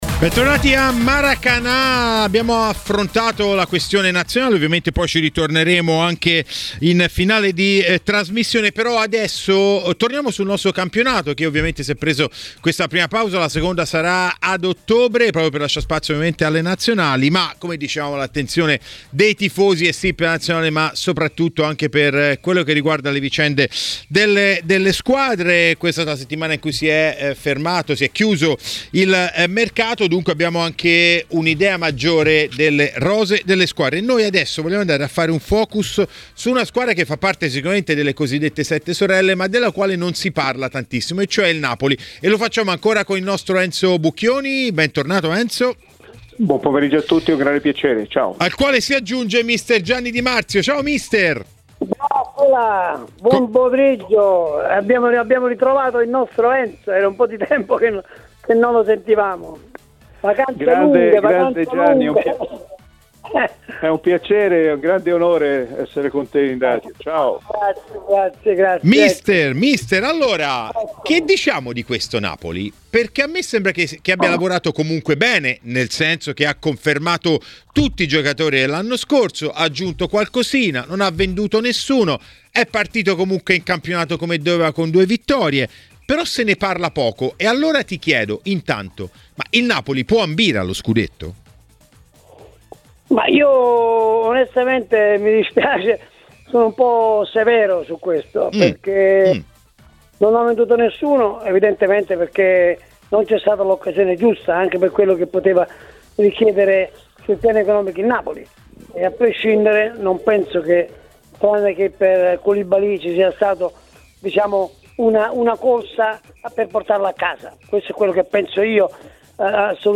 Per dire la sua sull'inizio di campionato del Napoli a Maracanà, nel pomeriggio di TMW Radio, è intervenuto mister Gianni Di Marzio.